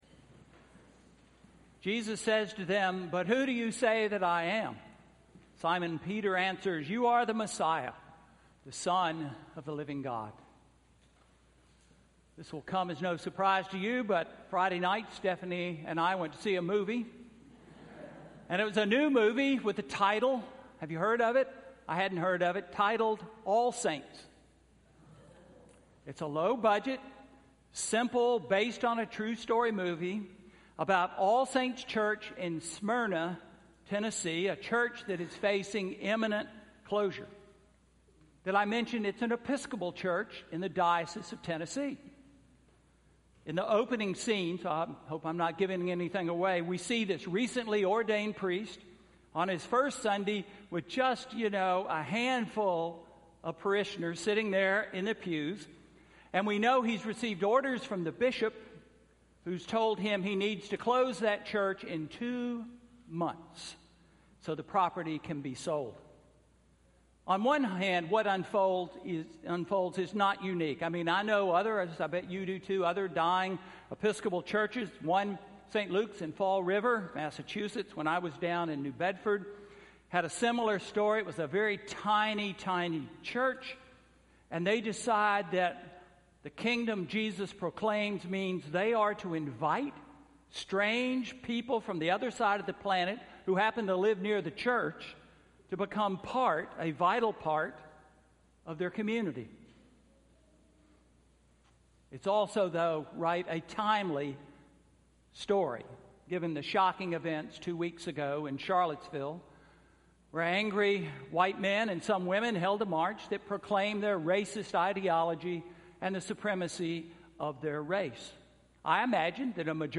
Sermon–August 27, 2017